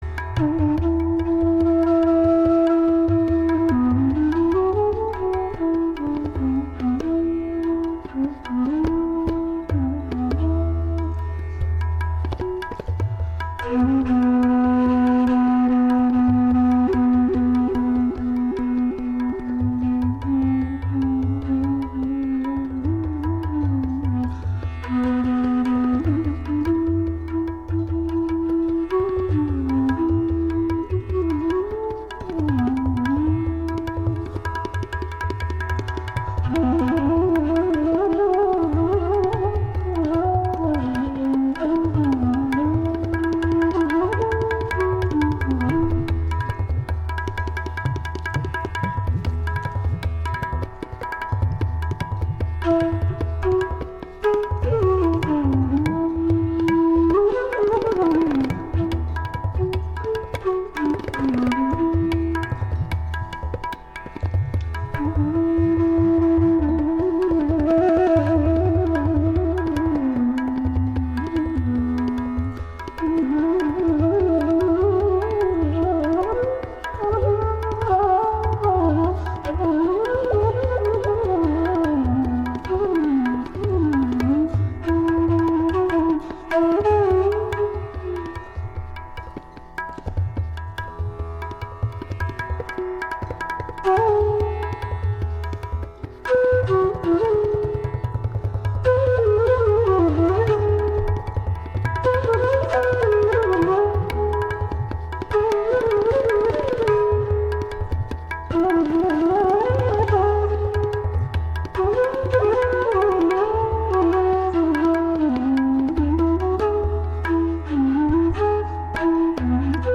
Genre: North Indian Classical.
Gat: Rupak Tal (7), Gat: Teental (16)   29:50